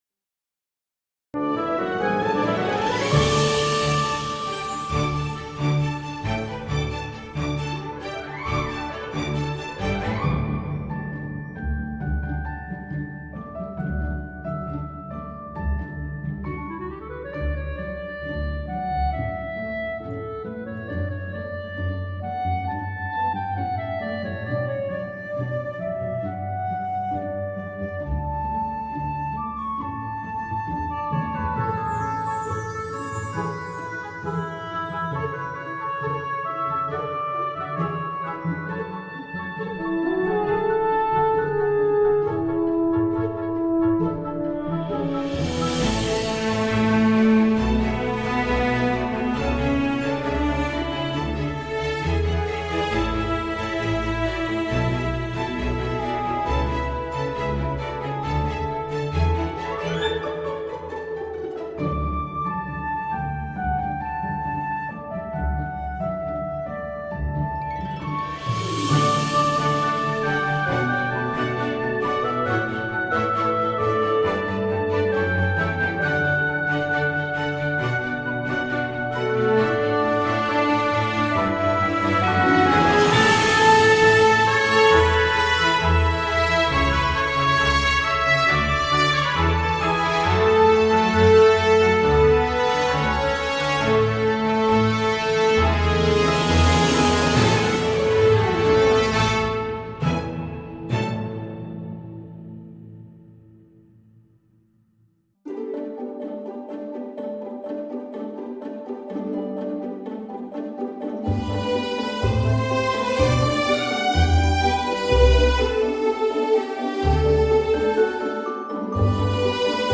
Música-Bosque-Mágico-Instrumental│Música-instrumental-relajante.mp3